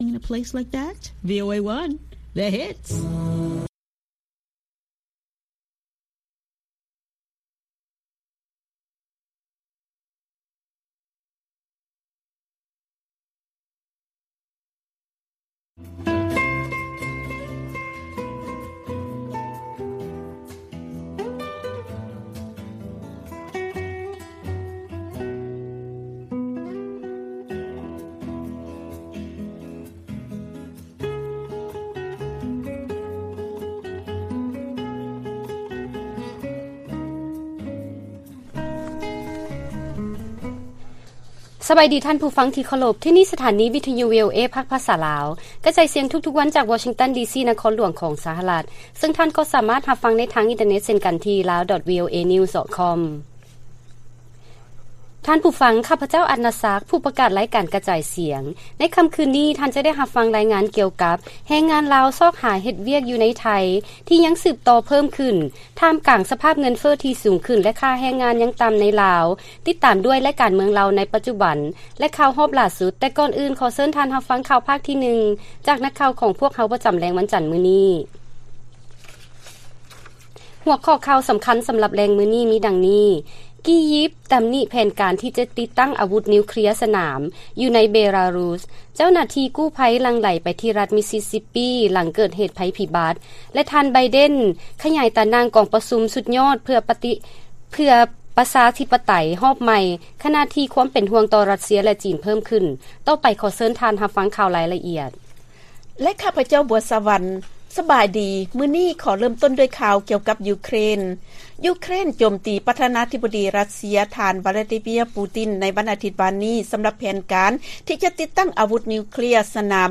ລາຍການກະຈາຍສຽງຂອງວີໂອເອ ລາວ: ກີຢິບ ຕຳໜິແຜນການ ທີ່ຈະຕິດຕັ້ງອາວຸດນິວເຄລຍສະໜາມຢູ່ ໃນເບລາຣຸສ